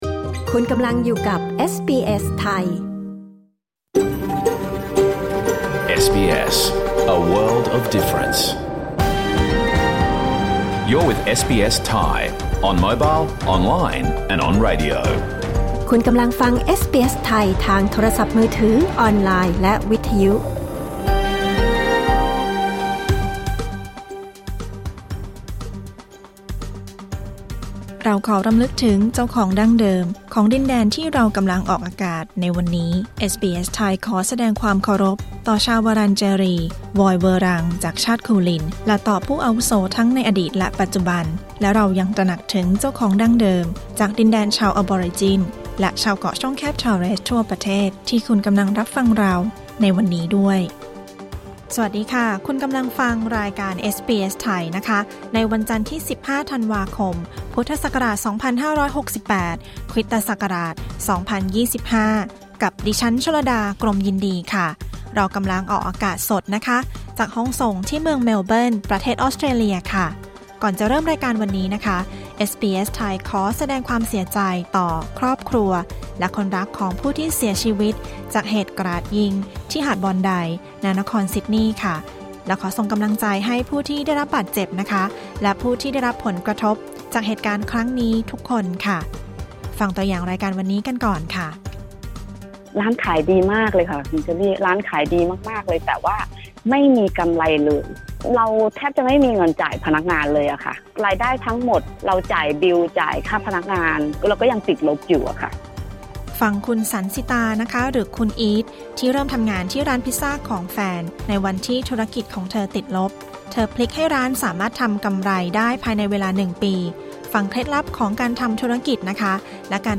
รายการสด 15 ธันวาคม 2568